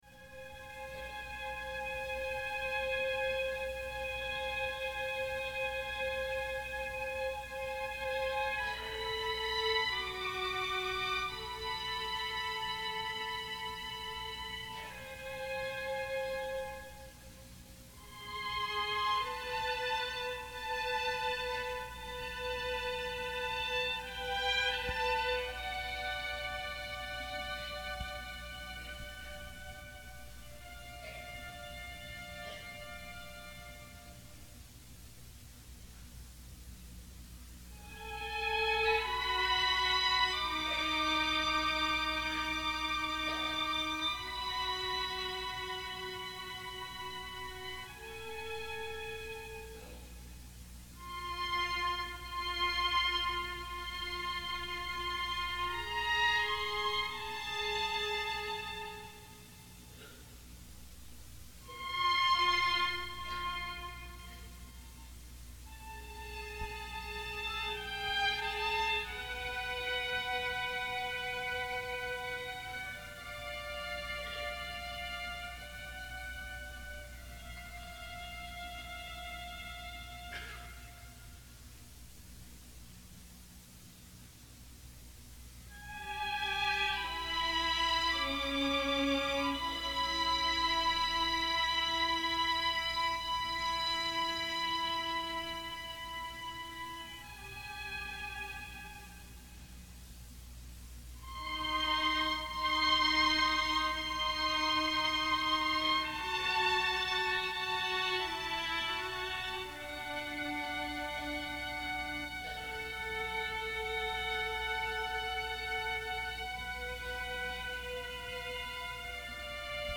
Timpani.Percussion(1).Piano(d Celesta): Strings